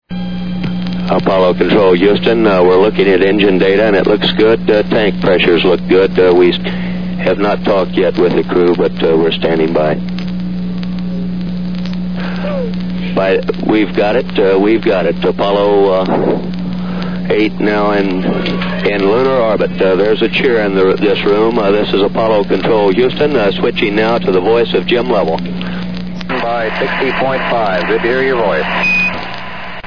Tags: ORIGINAL COMMUNICATIONS APOLLO MISSIONS NASA